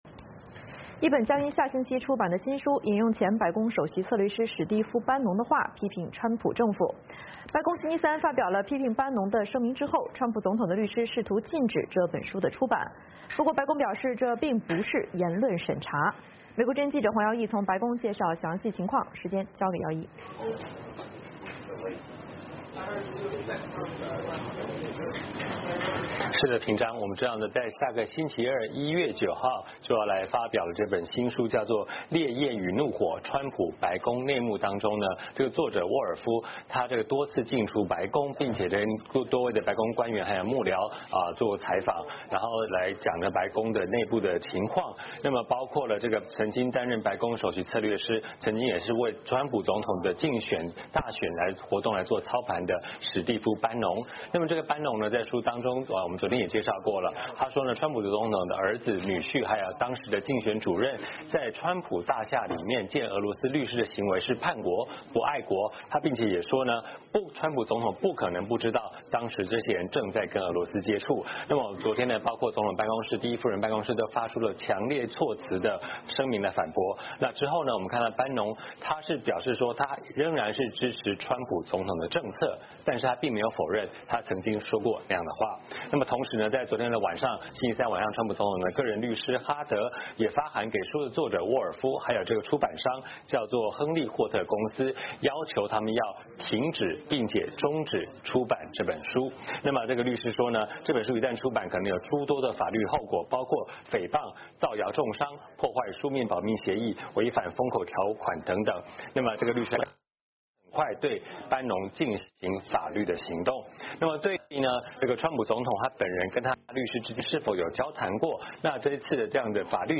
VOA连线